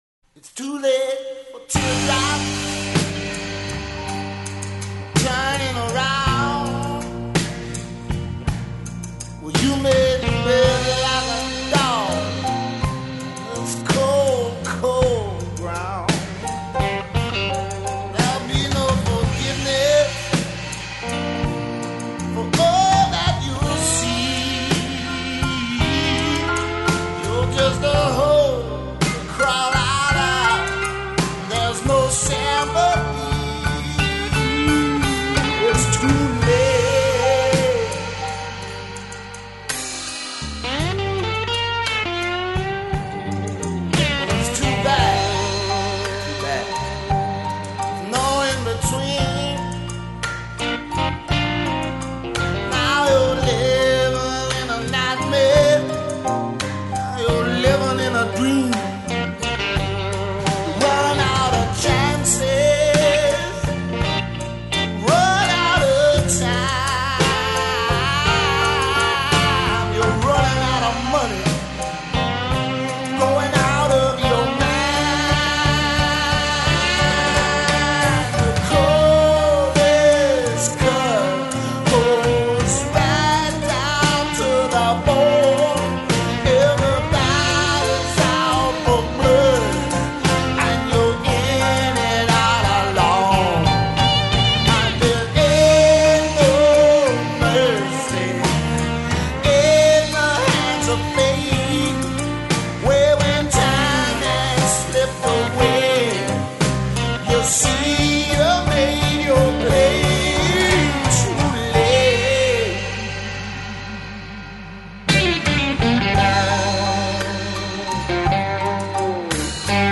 Guitars
Piano
Bass
Drums
Organ
Percussion